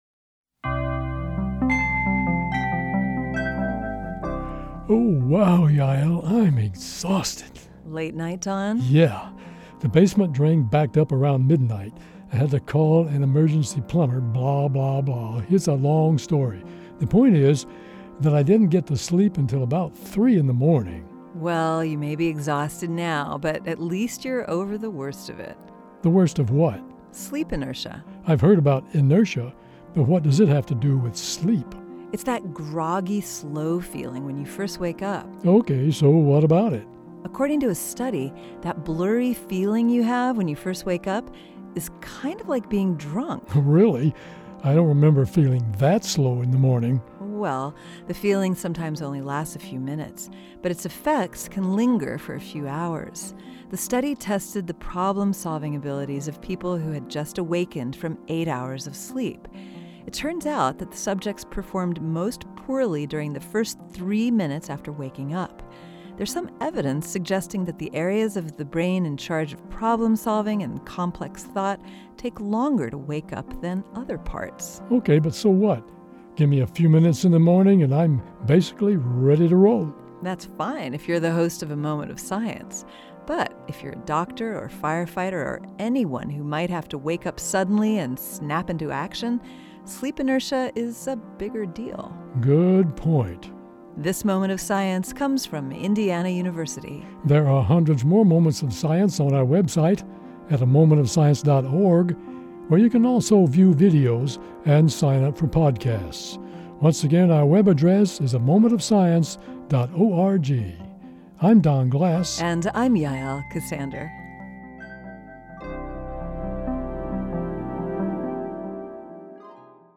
A Moment of Science is a daily audio podcast, public radio program and video series providing the scientific story behind some of life's most perplexing mysteries.